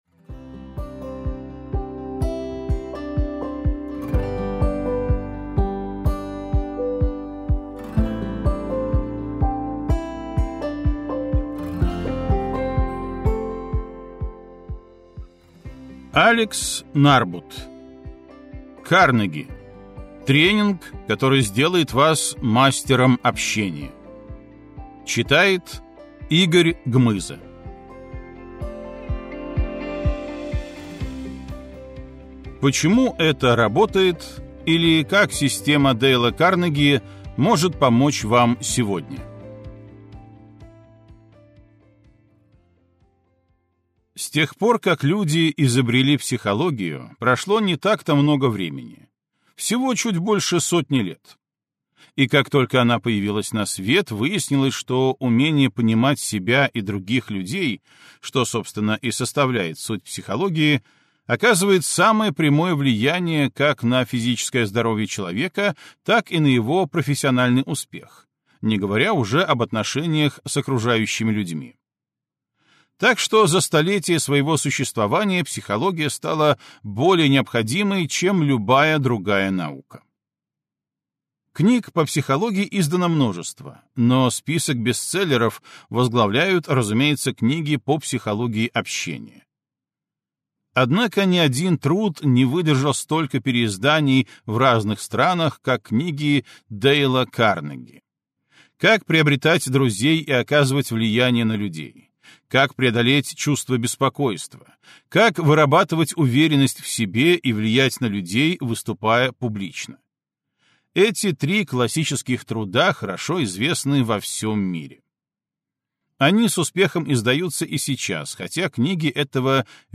Аудиокнига Карнеги: тренинг, который сделает вас мастером общения | Библиотека аудиокниг